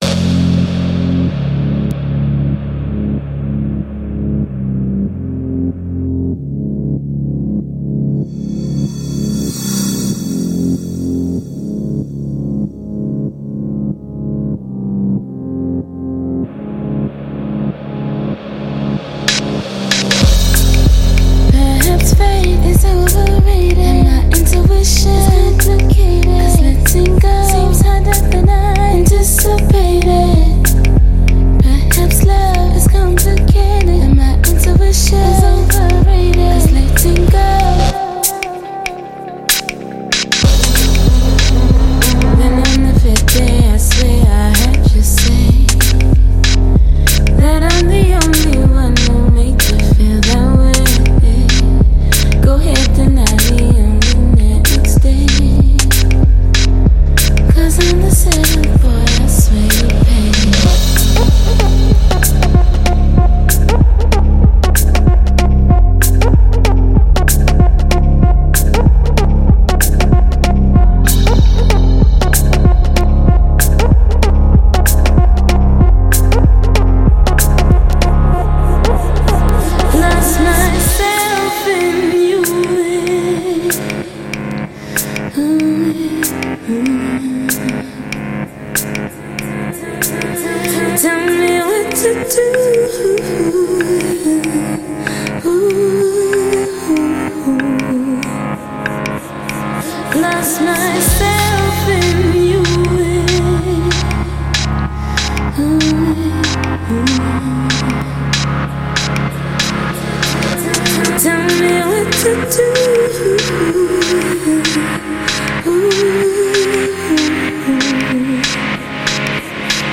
Soul
a sultry tale set to hazy beats and click percussion